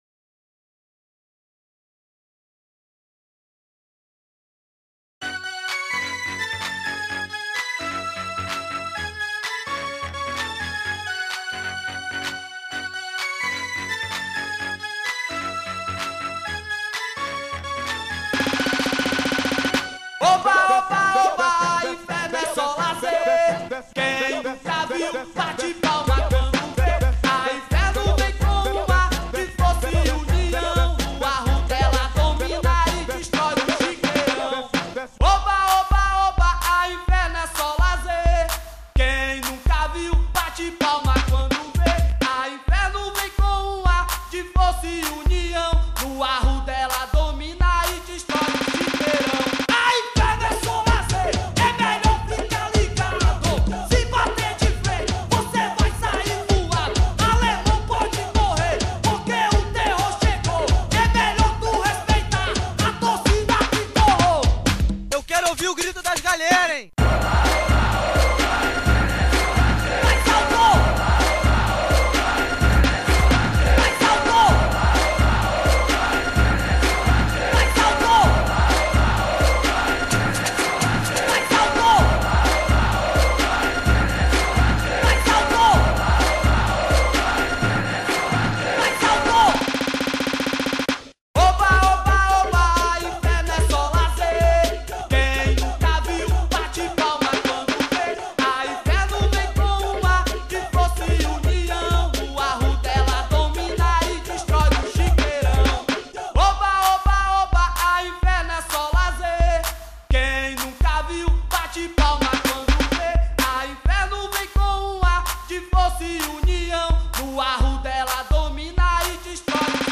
2024-12-16 22:43:20 Gênero: Rock Views